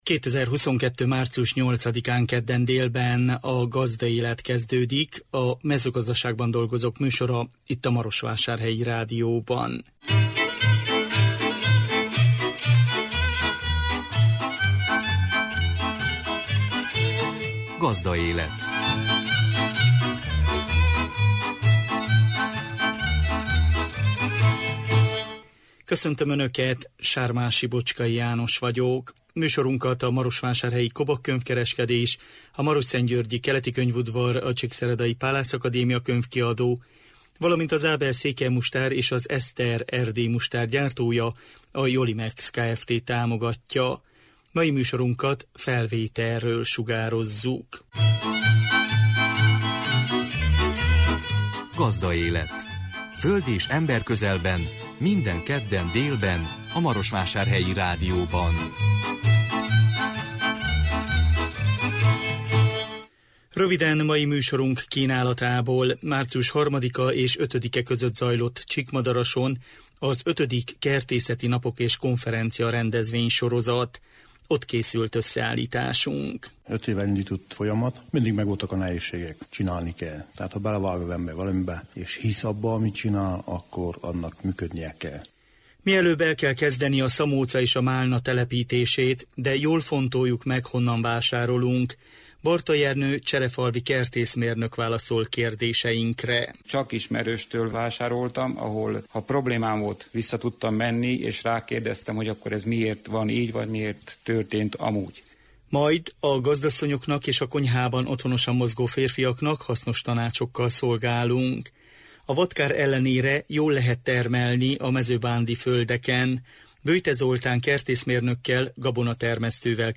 A 2022 március 8-án jelentkező műsor tartalma: Március 3-a és 5-e között zajlott Csíkmadarason az V. Kertészeti napok és konferencia rendezvénysorozat. Ott készült összeállításunk. Mielőbb el kell kezdeni a szamóca és a málna telepítését.